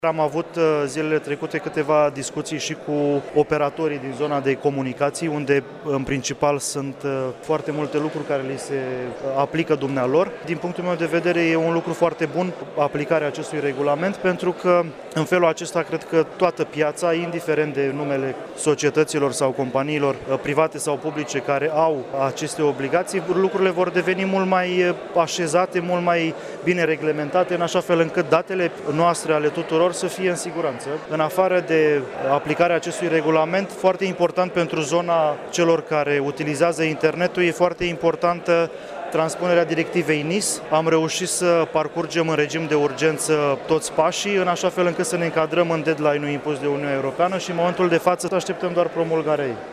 Ministrul Comunicaţiilor şi Societăţii Informaţionale, Bogdan Cojocaru, a mai declarat, la Iași, că de mâine, se va aplica şi în România, regulamentul european privind protecţia datelor şi a persoanelor fizice, iar această reglementare va impune reguli foarte clare tuturor operatorilor din telecomunicaţii:
24-mai-ora-13-B-Cojocaru-protectia-datelor.mp3